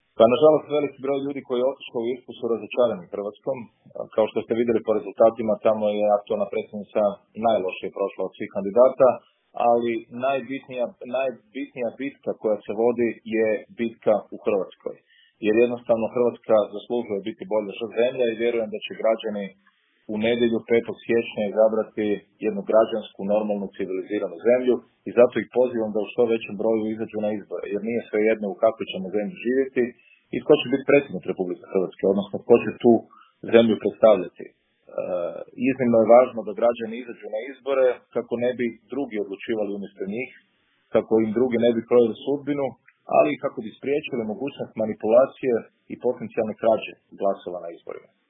Davor Bernardić u intervjuu Media servisa o aktualnoj predsjedničkoj kampanji